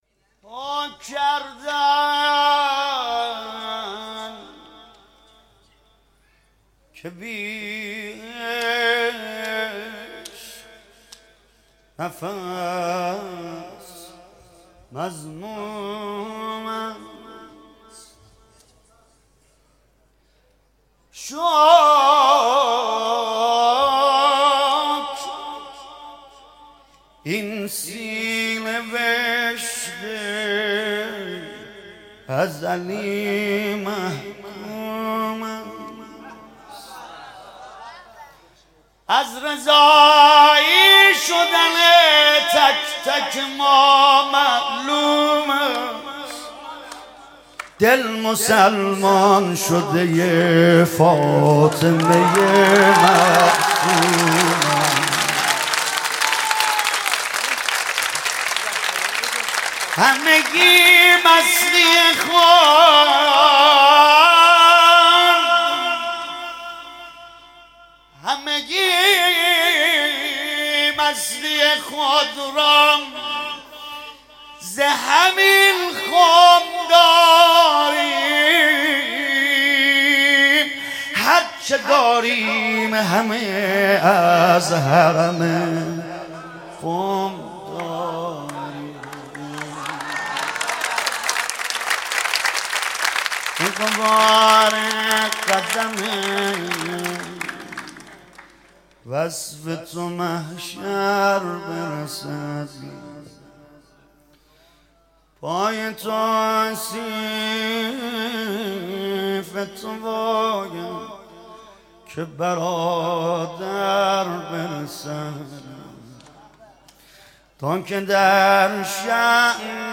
مدح: شکر این سینه به عشق ازلی محکوم است